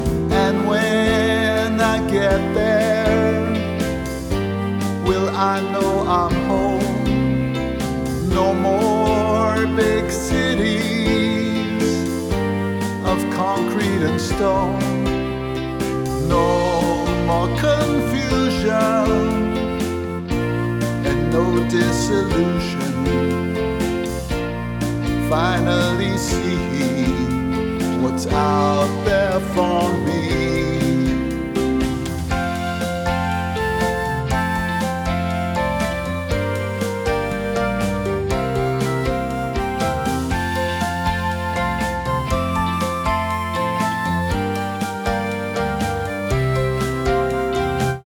A. Vocal Compositions